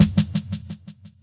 Snares
DRUMACHEEENSNR (1).wav